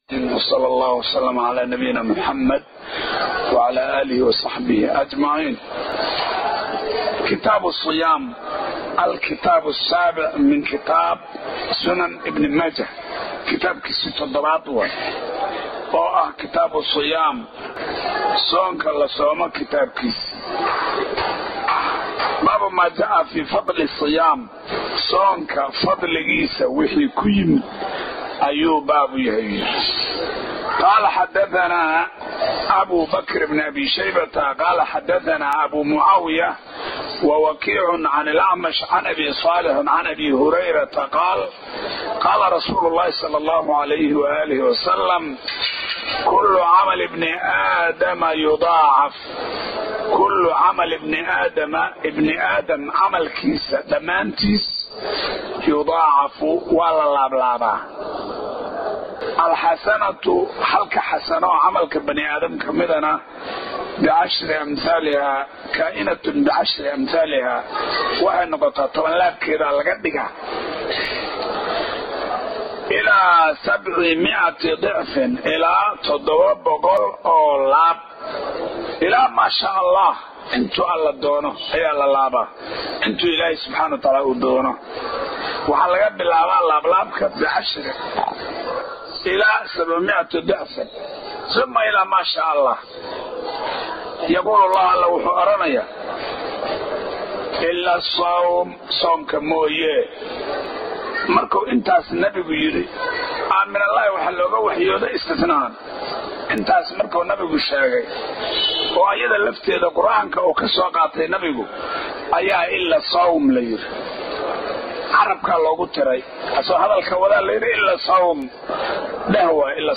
Sunnan ibnu Maaja – Kitaabu Siyaam – Darsiga 1aad